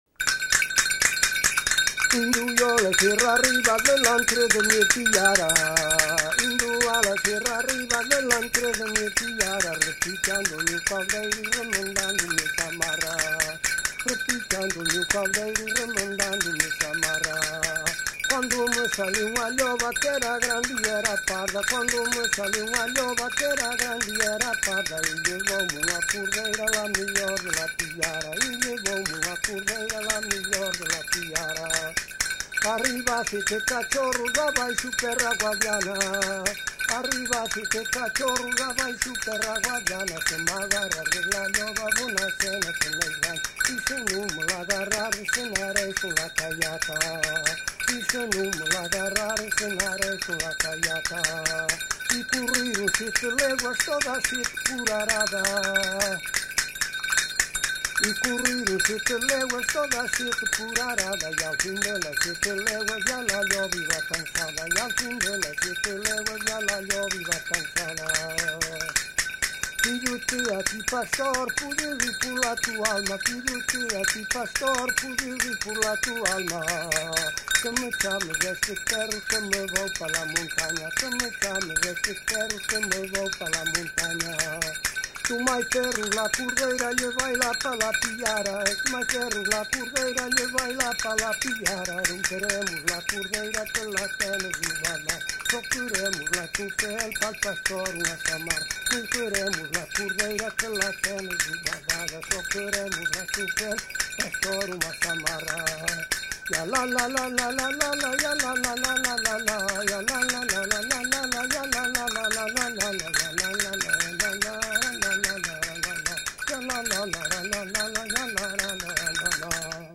Local: - Paradela, Miranda do Douro, Trás os Montes